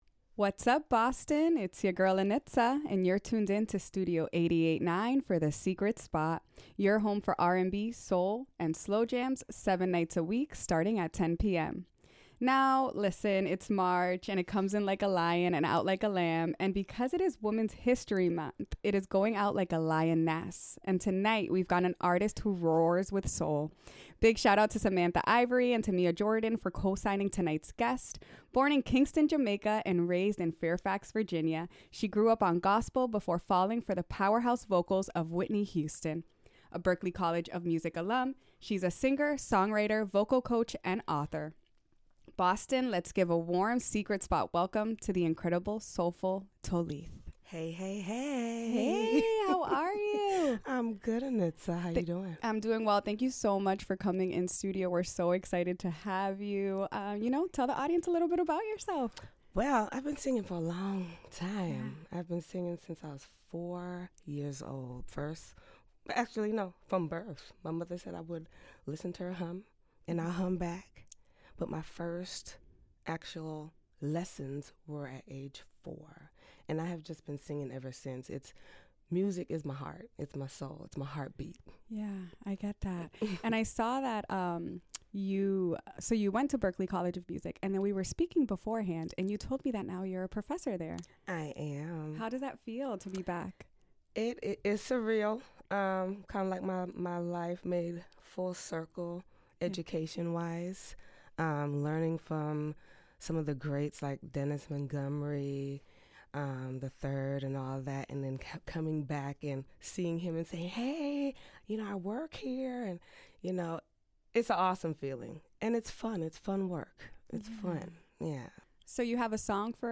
Studio 8-8-9! - WERS 88.9FM